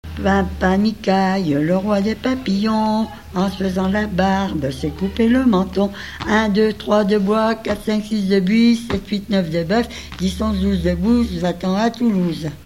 Mémoires et Patrimoines vivants - RaddO est une base de données d'archives iconographiques et sonores.
L'enfance - Enfantines - rondes et jeux
Pièce musicale inédite